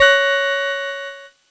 Cheese Chord 24-F#4.wav